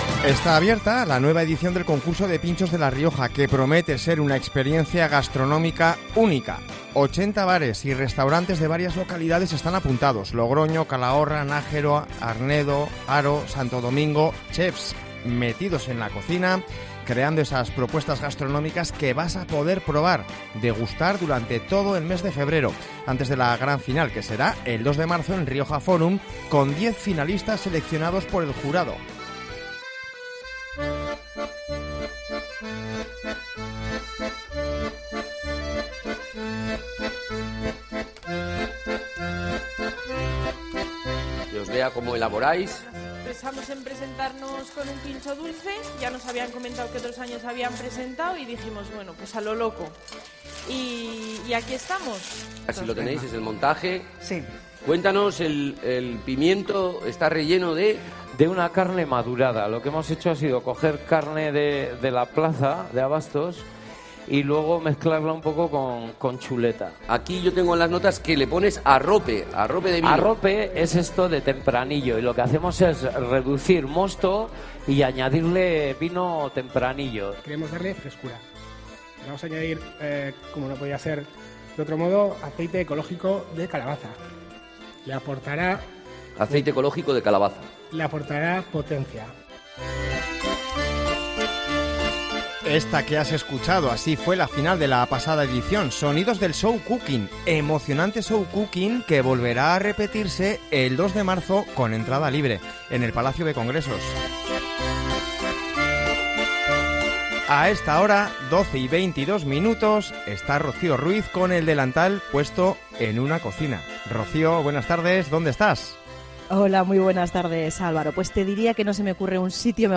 Escucha aquí esta entrevista de radio que se ha convertido en una receta de cocina a través de las ondas.